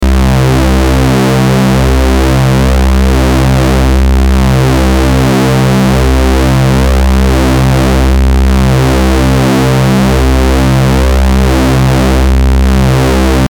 Я уже плохо помню JP-шку, но меня не покидает ощущение, что она не имеет ничего общего с оригинальной суперпилой JP-80x0. Биения осцилляторов в суперпиле какие-то слишком подозрительные, как будто она семплирована.